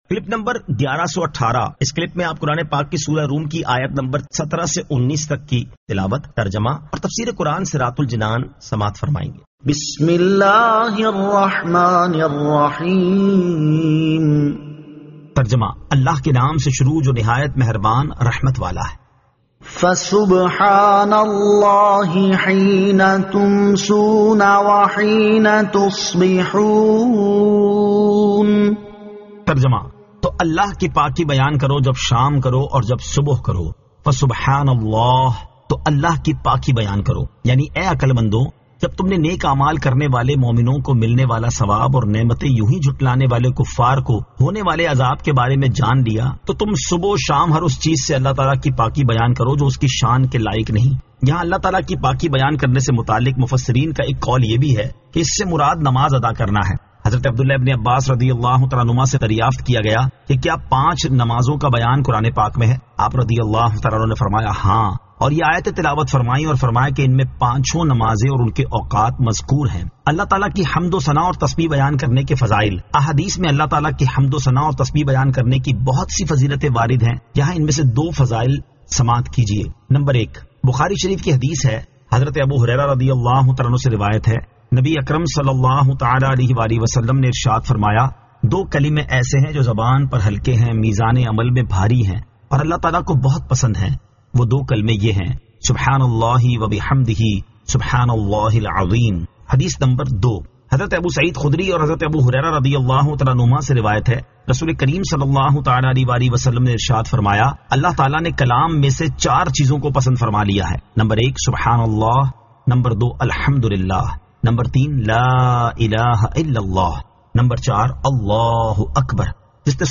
Surah Ar-Rum 17 To 19 Tilawat , Tarjama , Tafseer